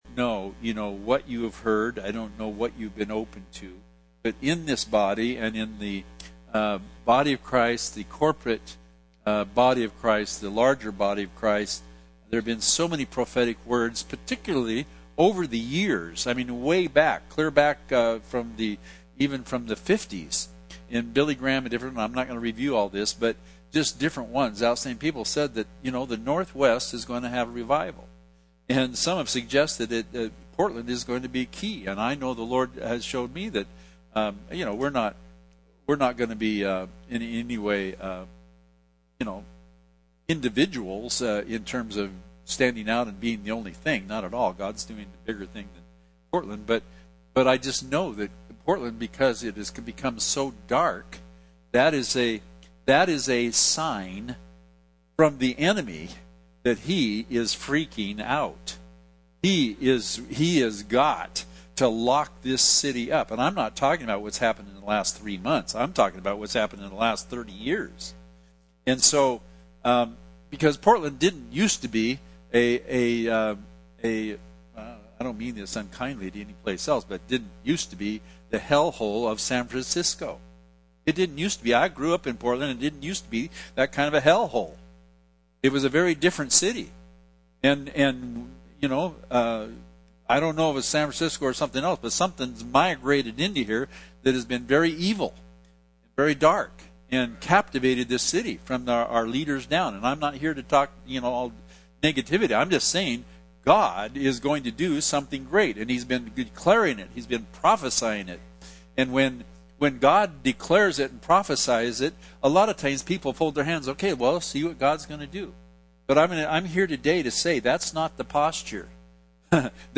Sermon 9/20/2020